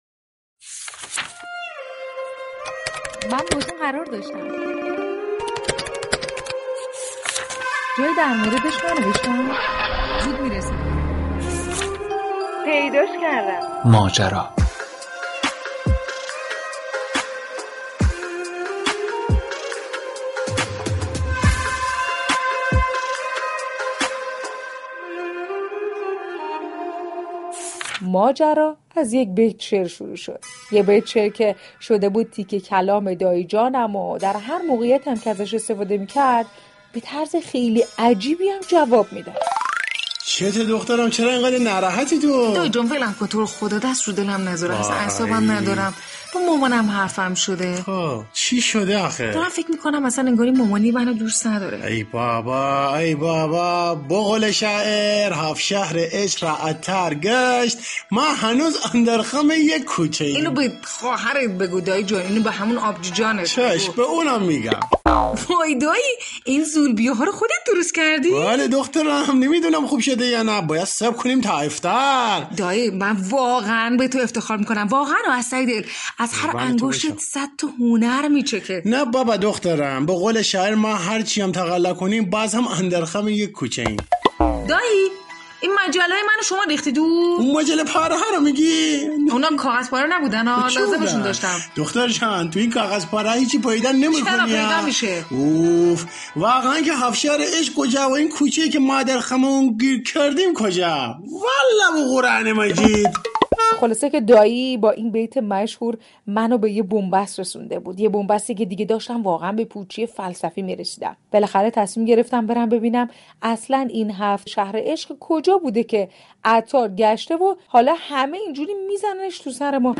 برنامه مستند«ماجرا» درروز بزرگداشت عطار با موضوع عرفان عطار نیشابوری همراه مخاطبان می شود.